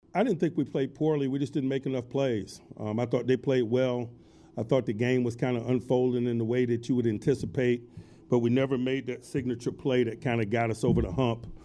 Steelers Head Coach Mike Tomlin said that he felt the team did not make enough plays.